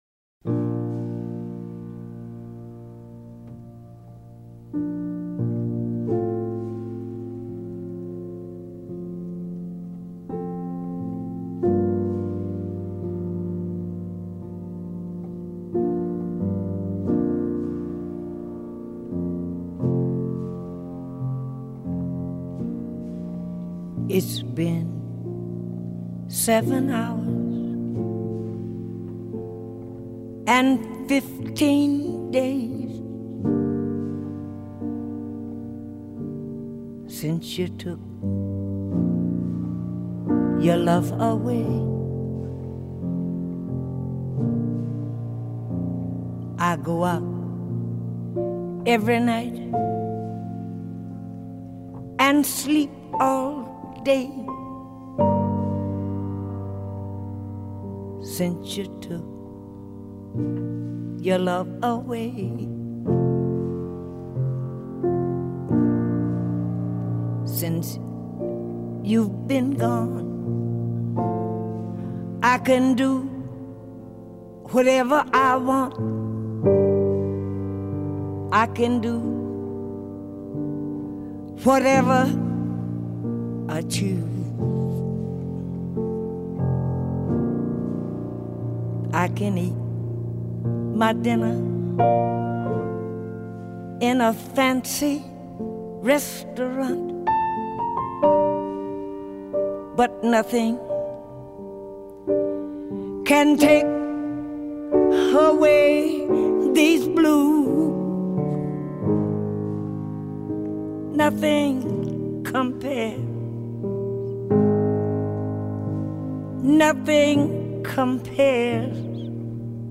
果然，这是张听了可使人内心平静的专辑。